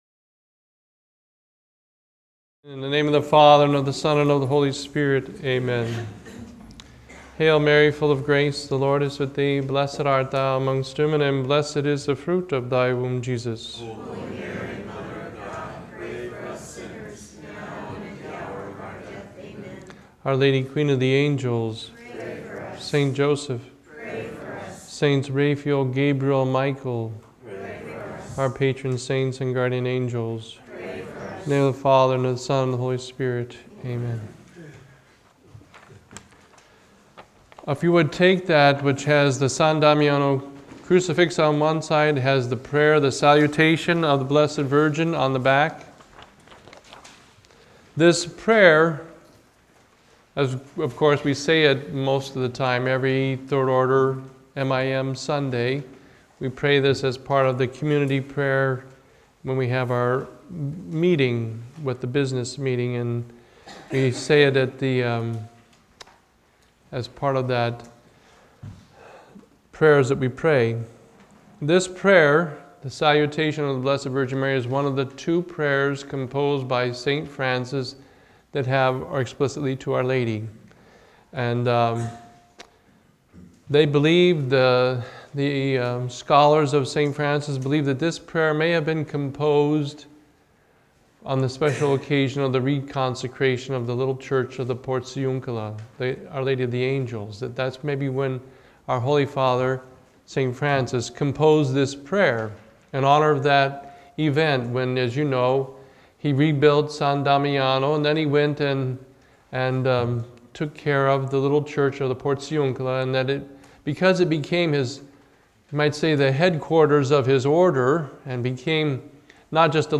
The annual M.I.M. retreat for 2012.